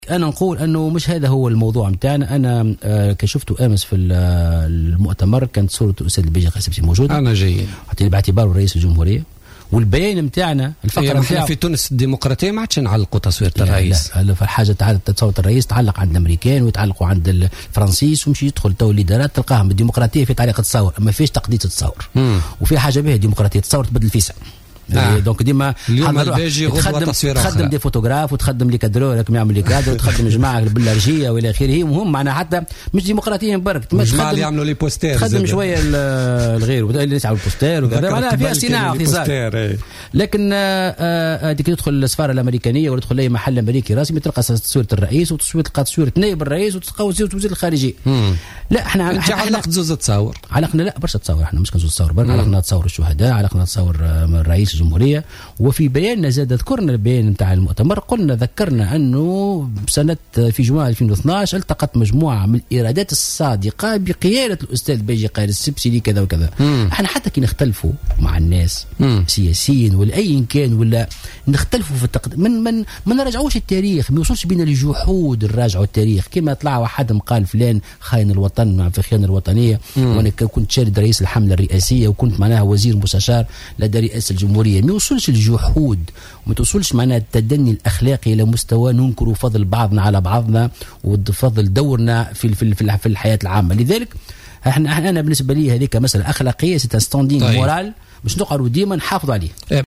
وقال مرزوق، ضيف برنامج "بوليتيكا" اليوم الاثنين إن الأمر يعتبر عاديا باعتبار وأن الباجي قائد السبسي رئيسا للجمهورية، وفق تعبيره.